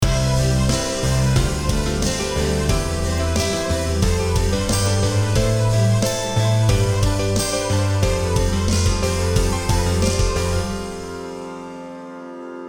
Now, let’s take as example me having recorded a track in my home studio. I’ve recorded the guitar, the piano and the drums using my digital condenser USB microphone onto different tracks in my sequencer software Cubase.
As I simply layer the tracks and play the song, the sound is quite undynamic and flat.
The first audio file is a raw recording, the only thing that I’ve done is layering the instruments.